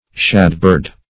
Search Result for " shadbird" : The Collaborative International Dictionary of English v.0.48: Shadbird \Shad"bird`\ (sh[a^]d"b[~e]rd), n. (Zool.)